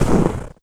High Quality Footsteps
STEPS Snow, Run 04.wav